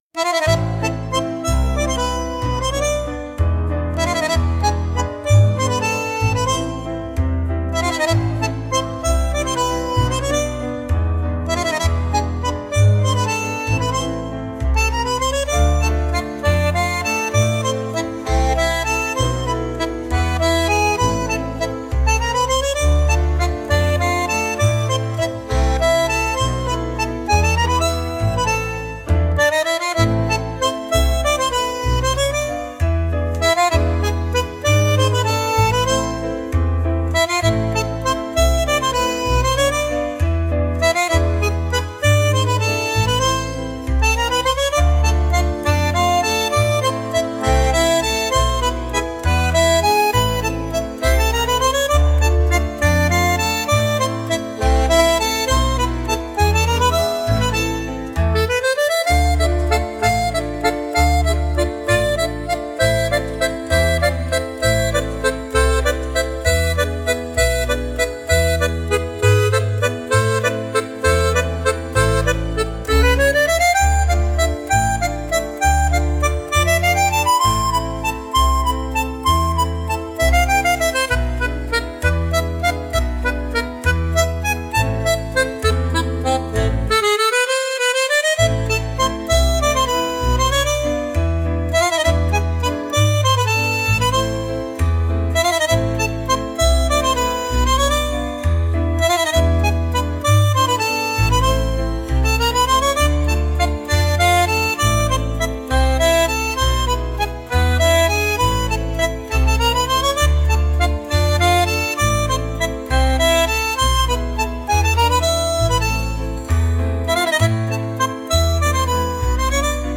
タンゴ特有の鋭いキレよりも、女性らしい「優しさ」や「柔らかさ」を表現するのに最適。
スローなテンポの中で、指先の軌跡や背中のアーチなど、身体のラインを丁寧に美しく見せることができます。